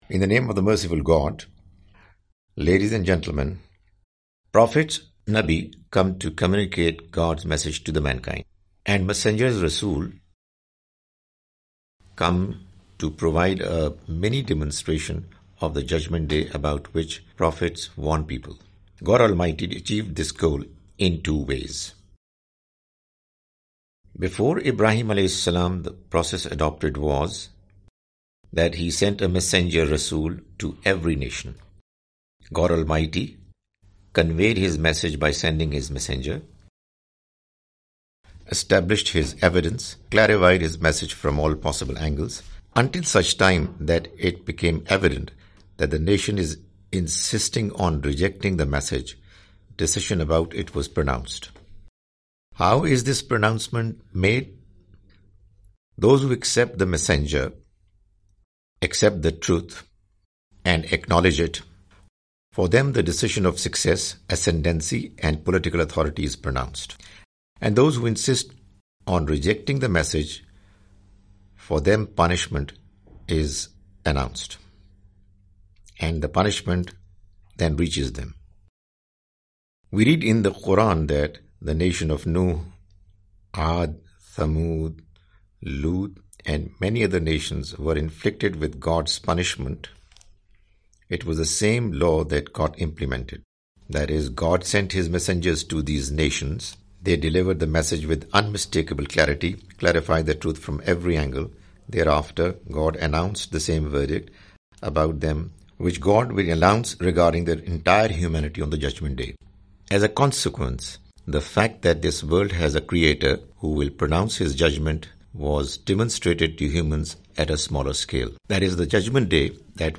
The Message of Qur'an (With English Voice Over) Part-11
The Message of the Quran is a lecture series comprising Urdu lectures of Mr Javed Ahmad Ghamidi.